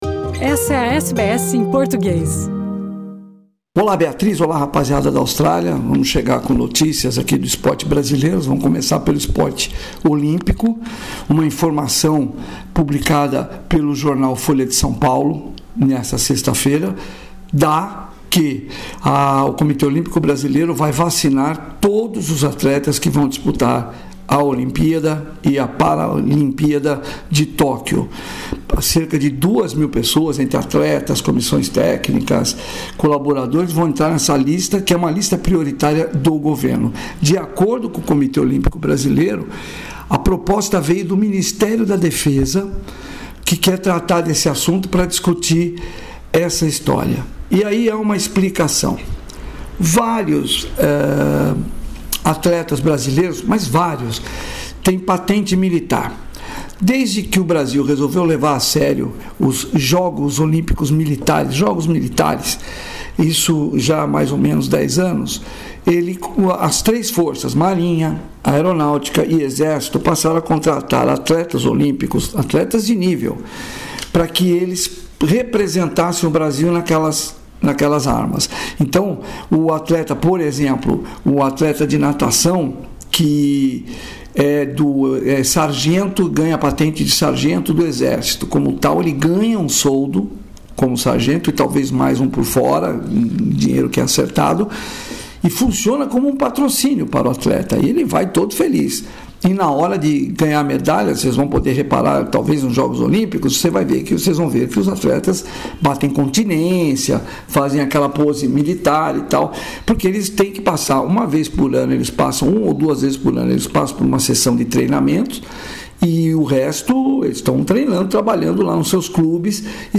boletim esportivo